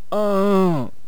archer_die5.wav